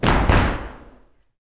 YOUTHFEET2-L.wav